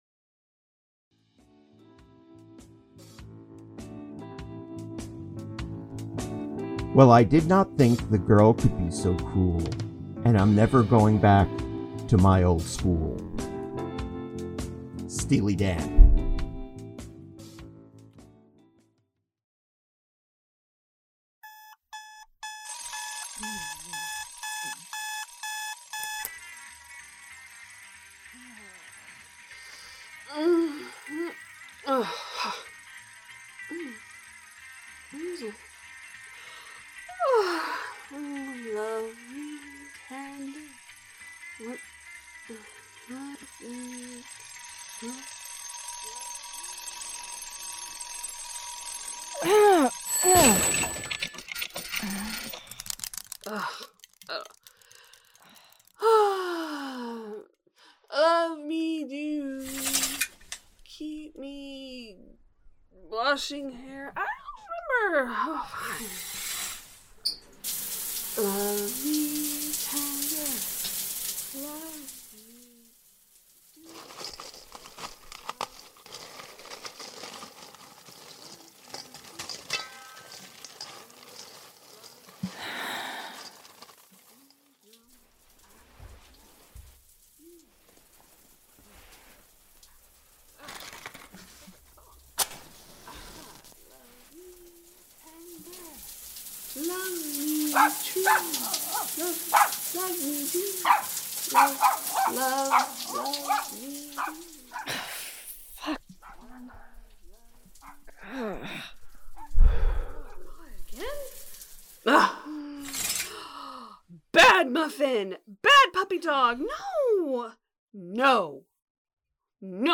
Strangers In Paradise – The Audio Drama – High School Omnibus – Two True Freaks
The Ocadecagonagon Theater Group